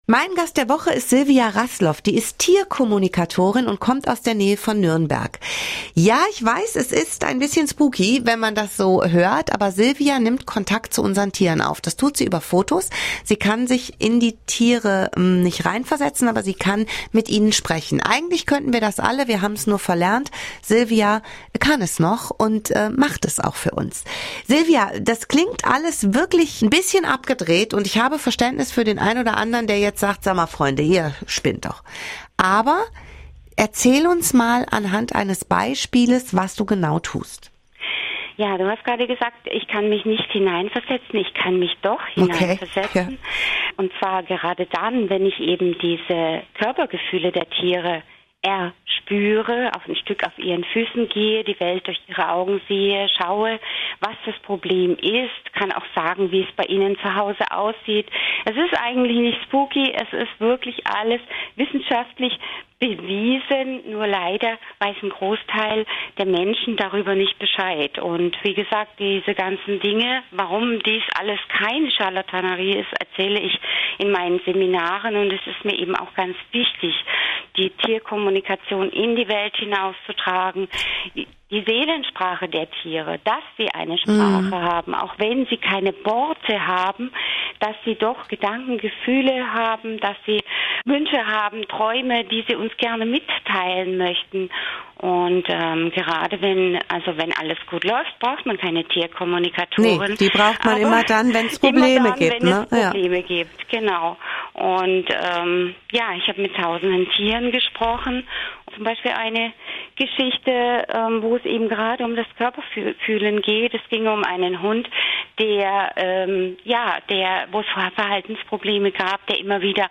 Mein Radio-Interview für Radio SALÜ…